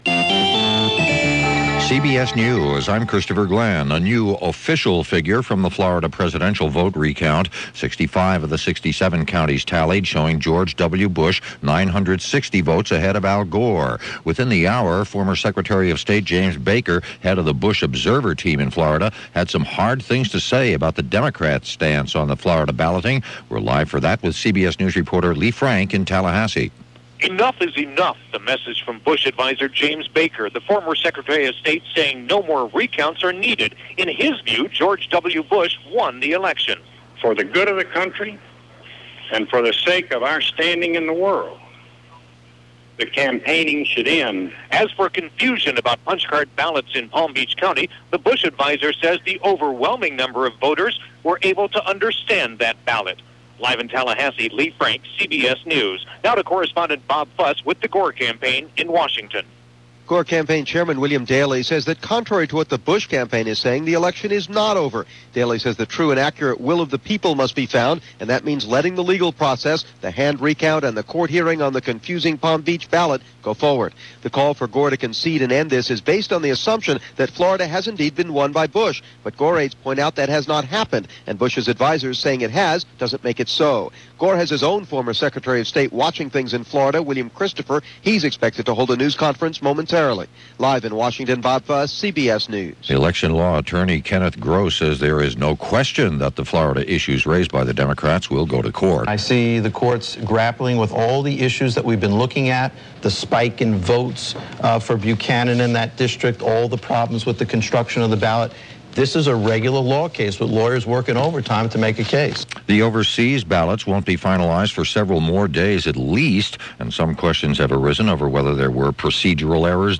CBS News On The Hour – Special Reports